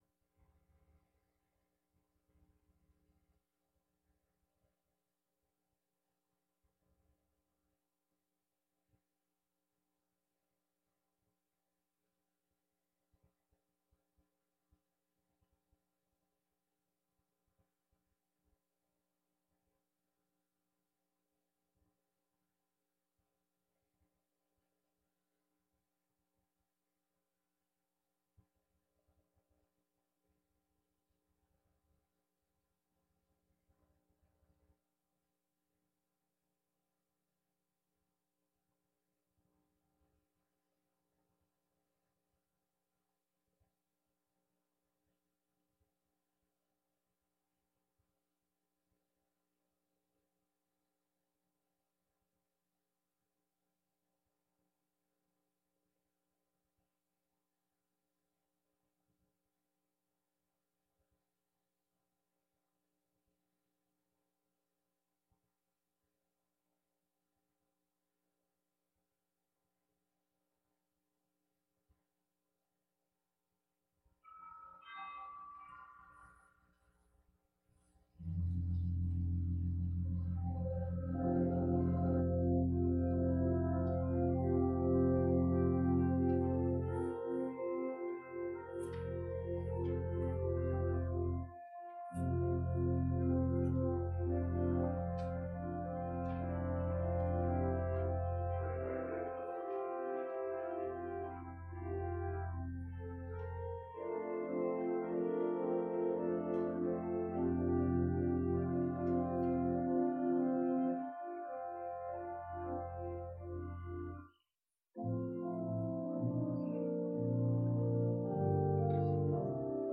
Passage: Jeremiah 31:7-14, John 1:[1-9] 10-18, Service Type: Sunday Worship Service